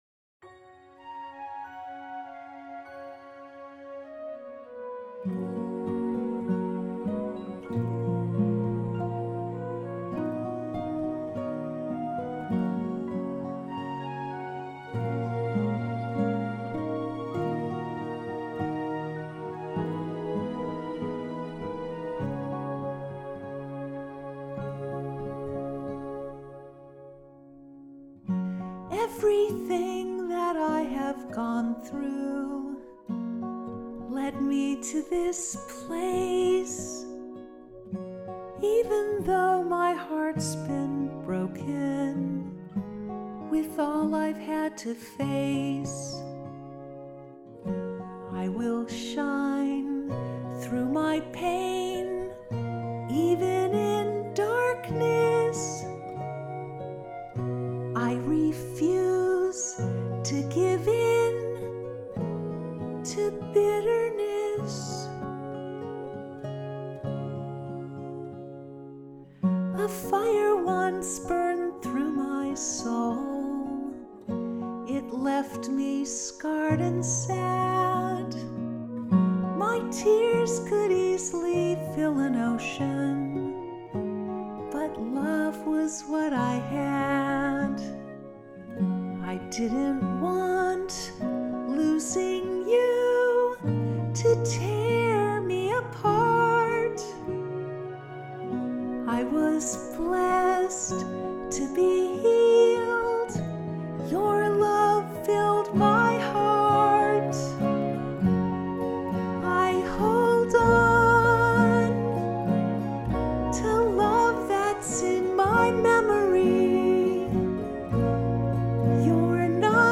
The three vocal songs for this medley carry a theme of looking up into the sky with sadness and awe.
acoustic guitar-based arrangement
somewhere-i-cant-see-vocal-arr-5-16-25.mp3